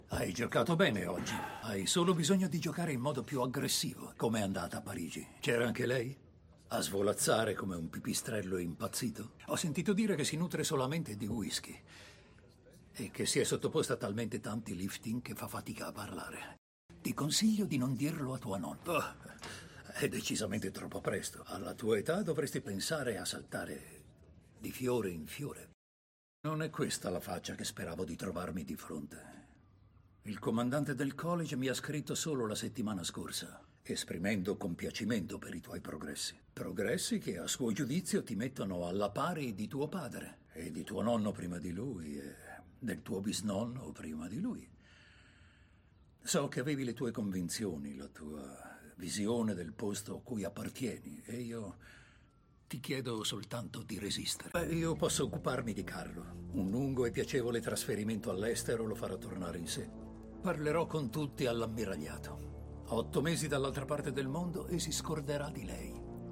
in cui doppia Charles Dance.